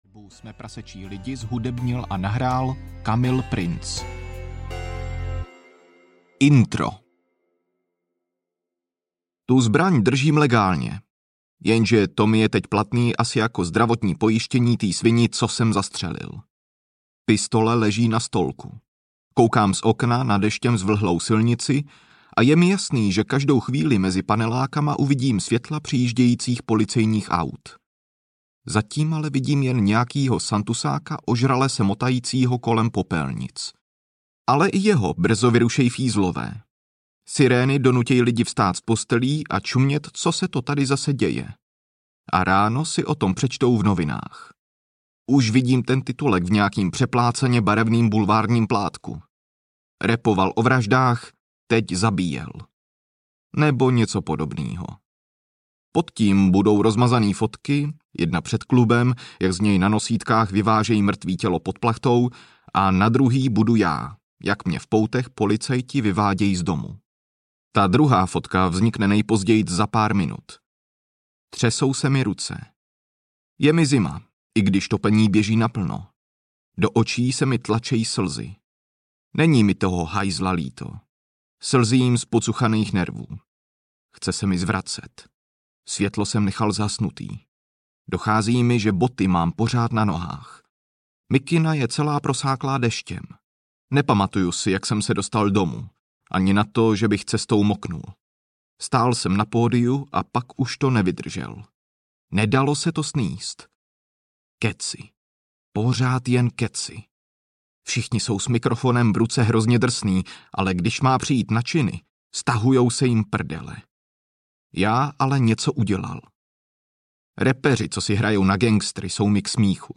Srab audiokniha
Ukázka z knihy